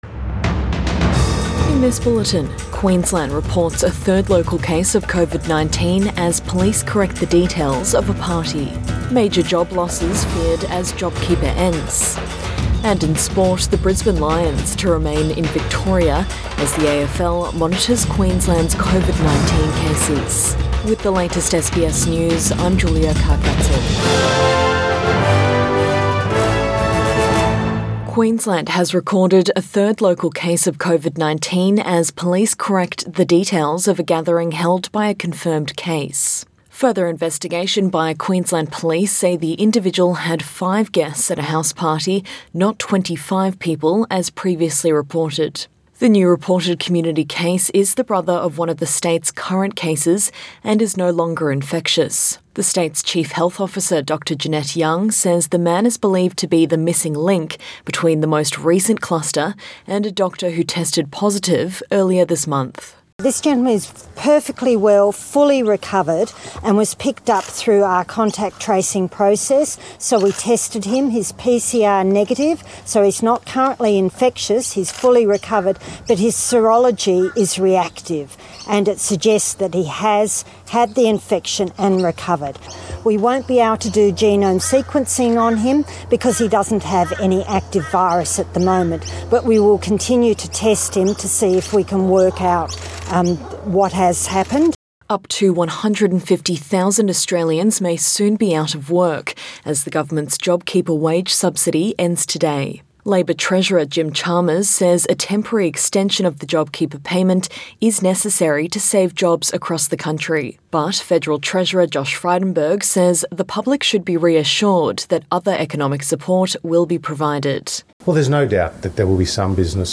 PM bulletin 28 March 2021